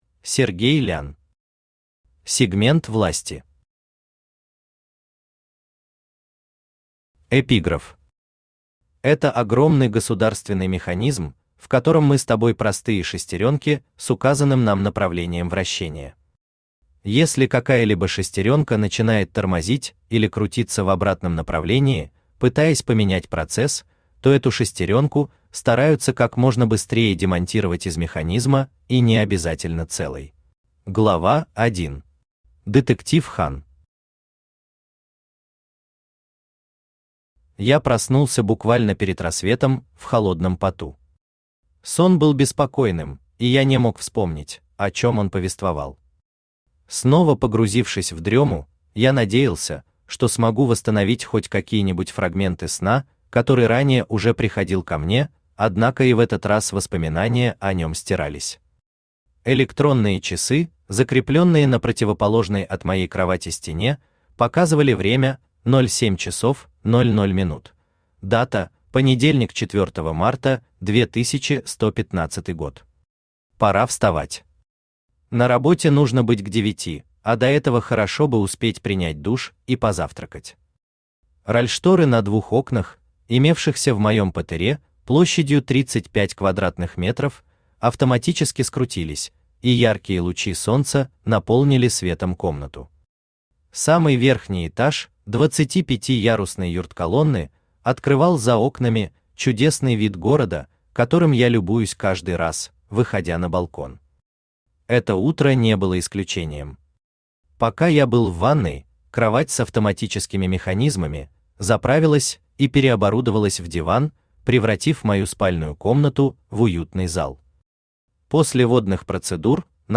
Аудиокнига Сегмент власти | Библиотека аудиокниг
Читает аудиокнигу Авточтец ЛитРес.